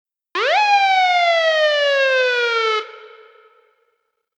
lwsiren-siren.ogg